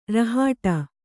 ♪ rahāṭa